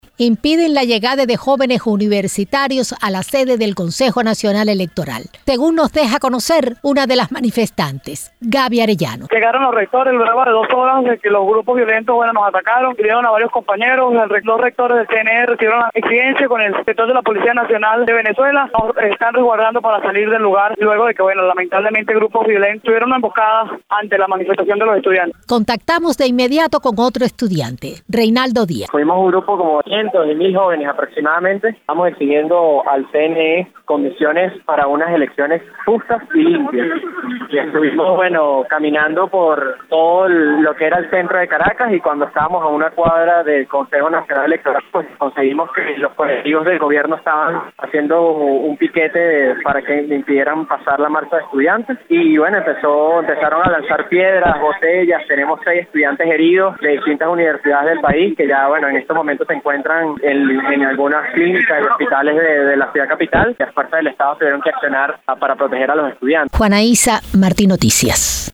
recogió declaraciones de dos estudiantes que participaban en la marcha y tiene el informe.